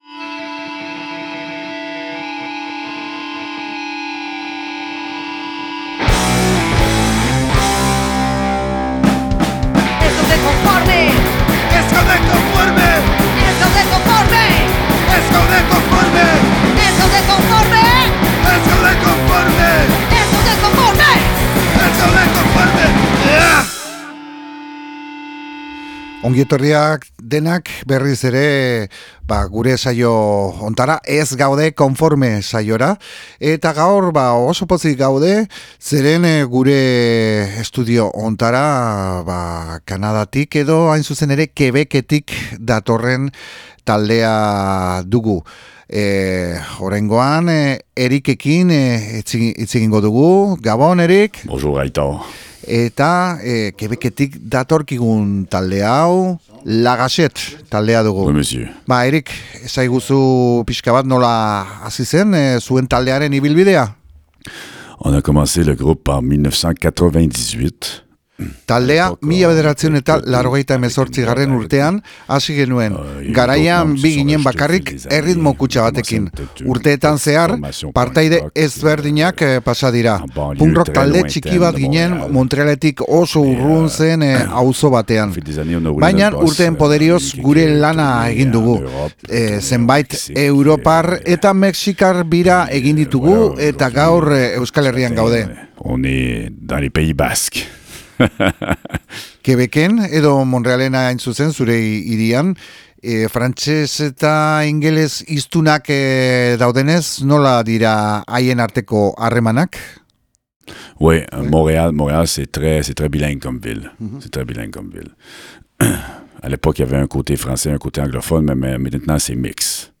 LA GACHETTE, Montrealetik datorren Oi! talde Quebec-tarra dugu.